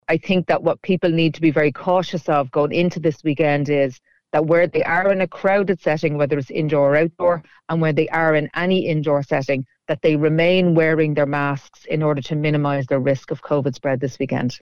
Professor of Immunology